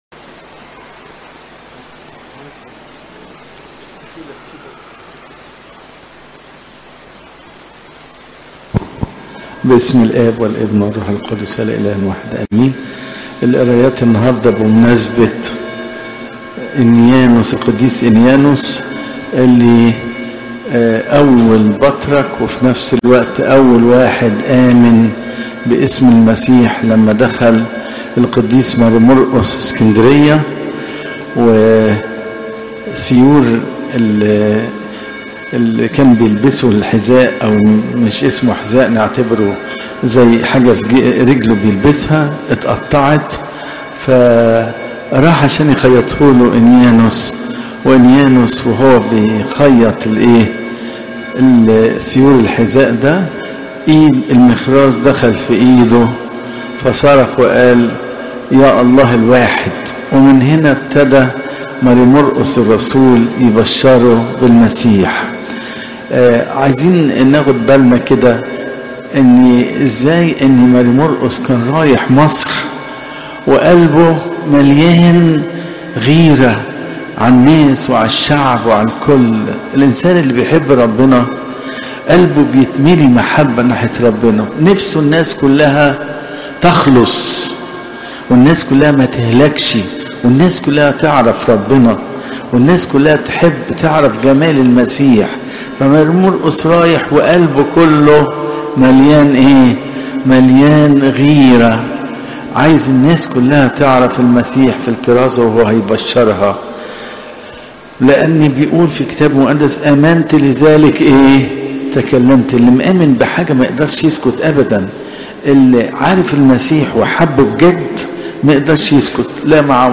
عظات قداسات الكنيسة صوم الميلاد (مر 11 : 1 - 11)